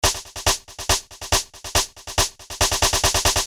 Tag: 140 bpm House Loops Percussion Loops 590.80 KB wav Key : Unknown